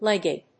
/lég(ɪ)d(米国英語), ˈlegʌd(英国英語)/